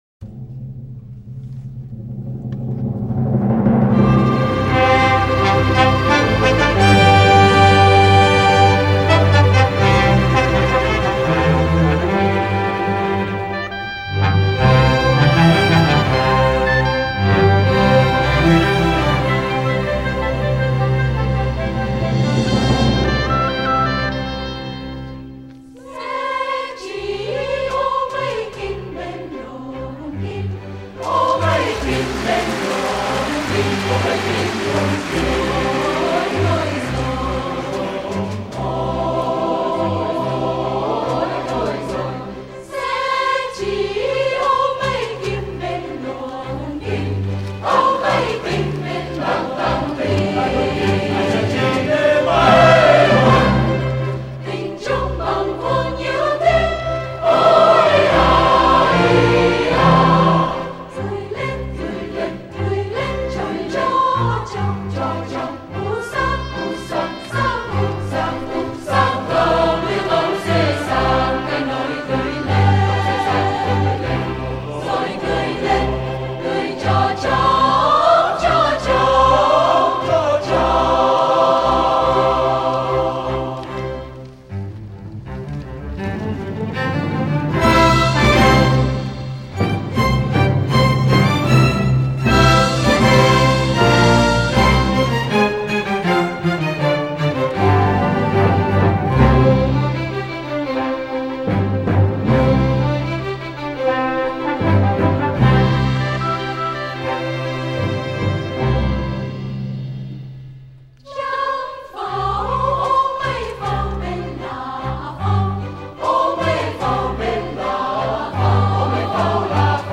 Xe Chỉ Luồn Kim– Dân ca do Phạm Duy sưu tập.
Trình Bày: Ban Hợp ca Ngàn Khơi